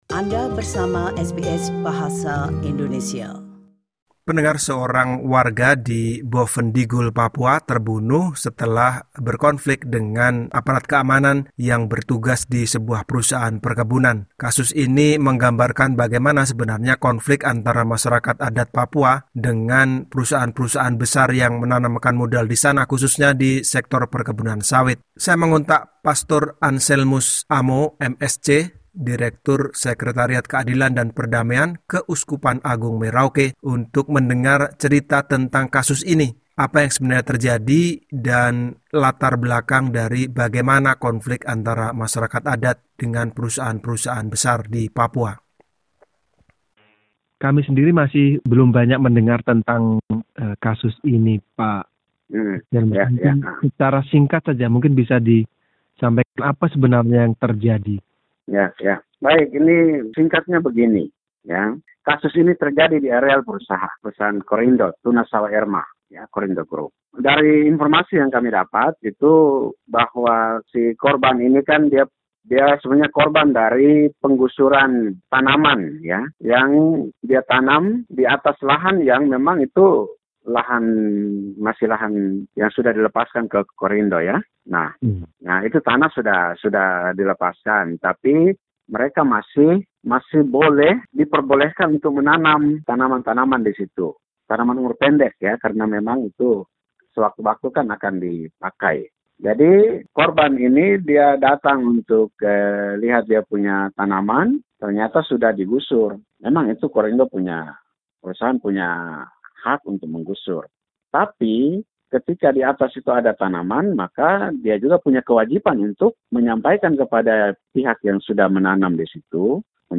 wawancara berikut